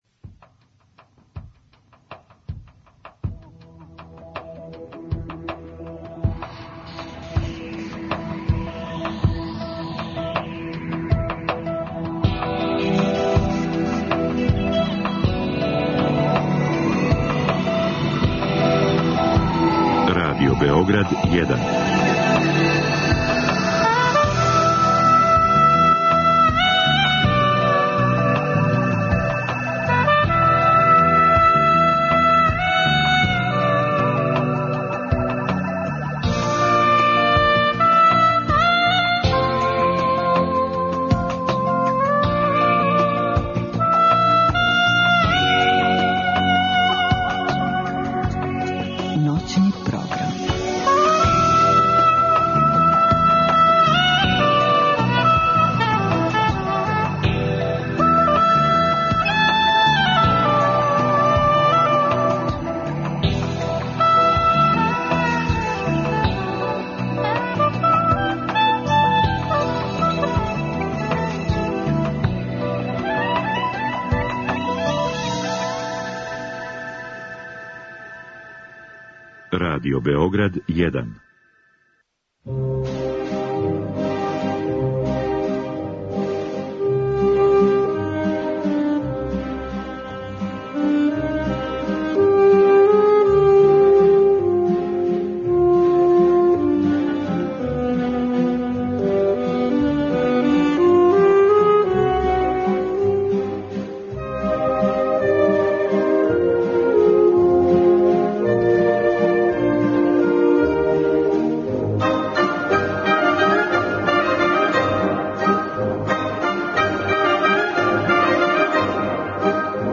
У термину од 2-4 , поред музике нишких аутора, слушаћете и интерпретације уметника који су или потекли из Ниша, или данас представљају водеће фигуре нишког музичког живота.